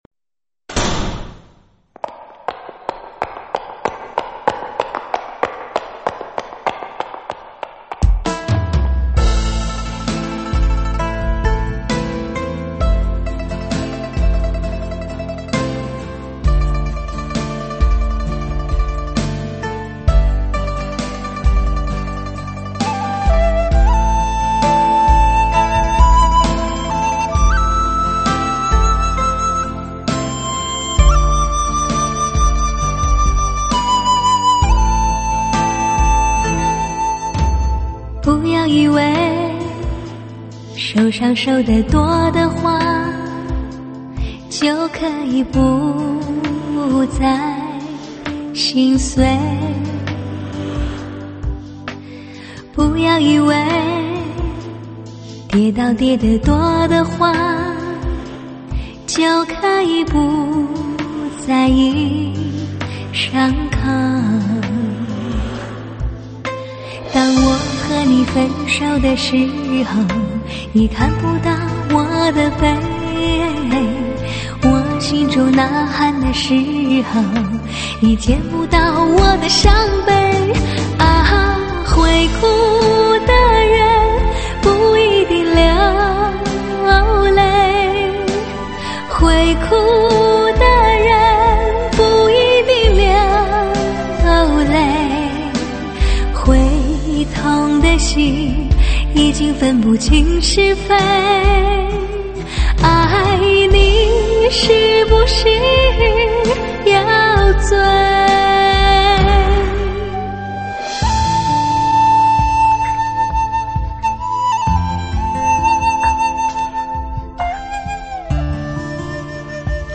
音质： 320 Kbps